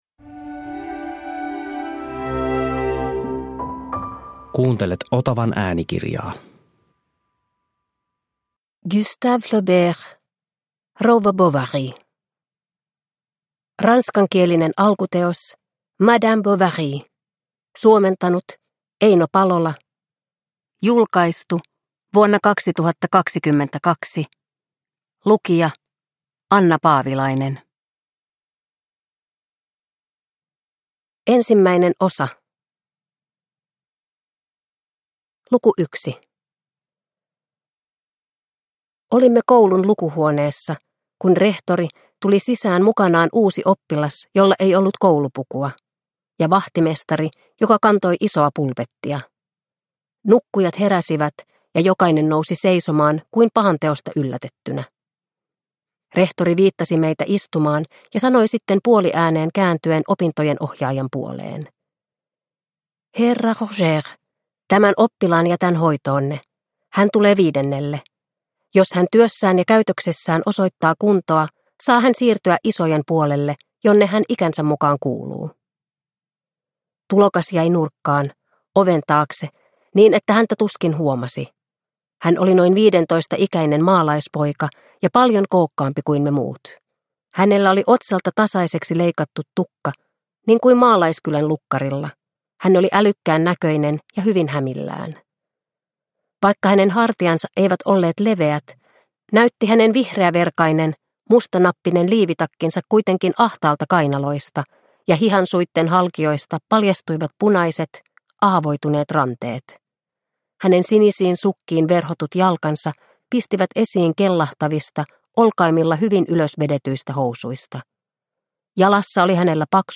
Rouva Bovary – Ljudbok – Laddas ner